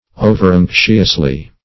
Search Result for " overanxiously" : The Collaborative International Dictionary of English v.0.48: Overanxious \O"ver*anx"ious\, a. Anxious in an excessive or needless degree. -- O"ver*anx"ious*ly , adv.
overanxiously.mp3